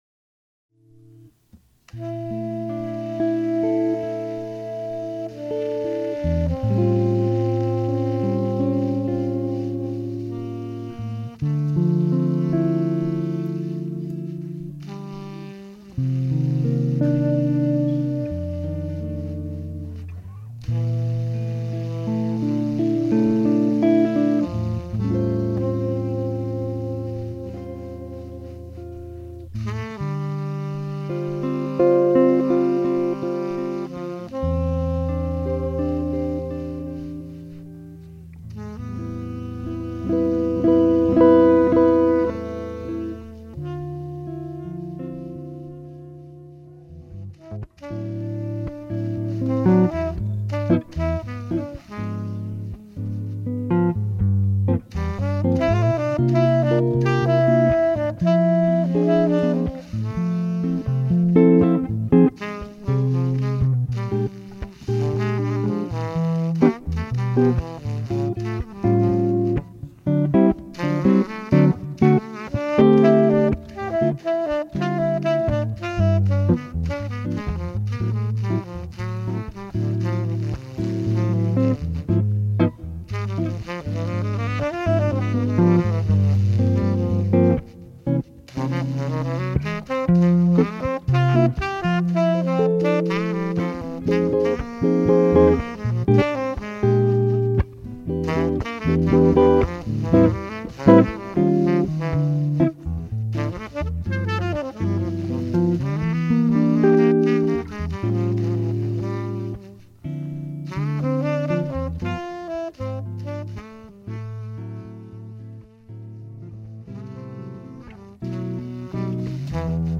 (gitara i saksofon)